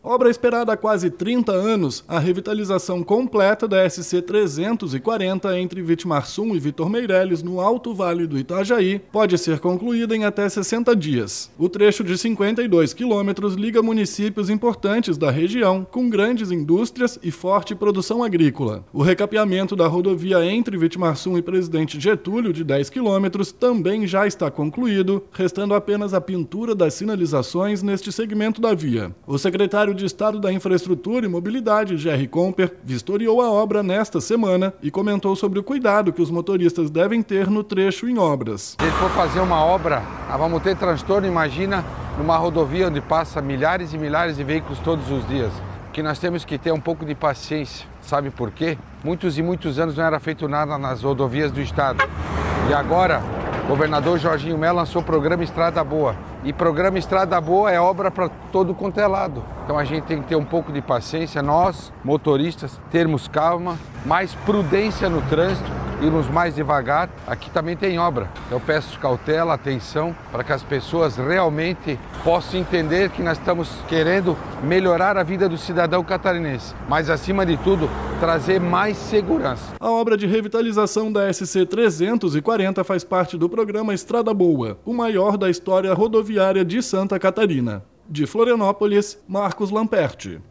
BOLETIM – Infraestrutura atua em mais de 50 quilômetros da revitalização da SC-340, no Alto Vale
O secretário de Estado da Infraestrutura e Mobilidade, Jerry Comper, vistoriou a obra esta semana e comentou sobre o cuidado que os motoristas devem ter no trecho em obras: